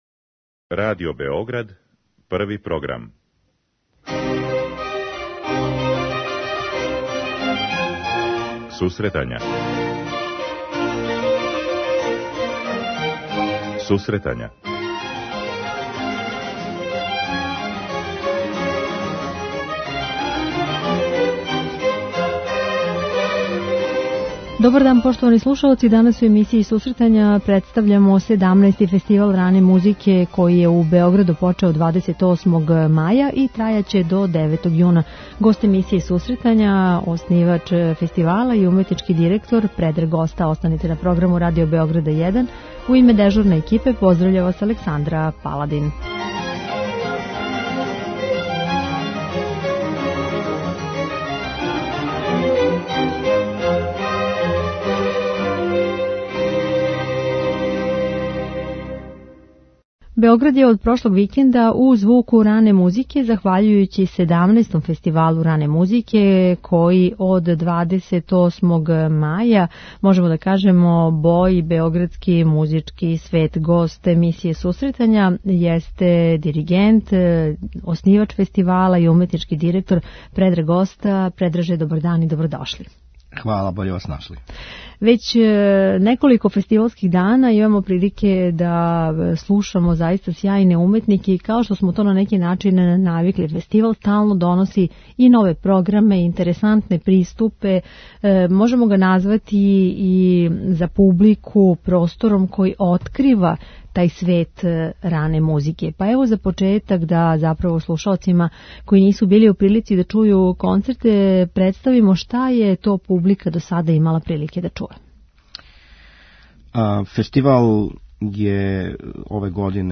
Емисија за оне који воле уметничку музику.